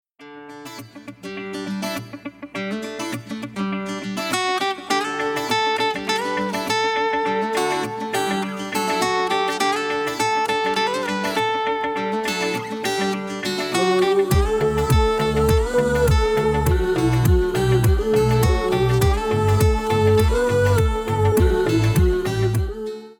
melodious instrumental cover
Category: Instrumental Ringtones